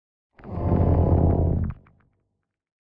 Minecraft Version Minecraft Version snapshot Latest Release | Latest Snapshot snapshot / assets / minecraft / sounds / mob / warden / ambient_2.ogg Compare With Compare With Latest Release | Latest Snapshot